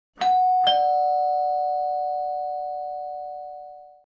Door Bell Sound Effect Free Download
Door Bell